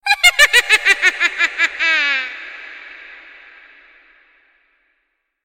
دانلود صدای خنده جادوگر از ساعد نیوز با لینک مستقیم و کیفیت بالا
جلوه های صوتی
برچسب: دانلود آهنگ های افکت صوتی انسان و موجودات زنده